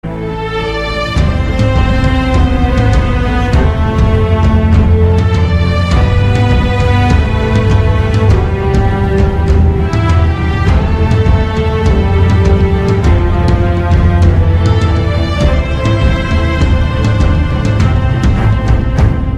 • Качество: 128, Stereo
без слов
Музыка из последней части культовой экшн-игры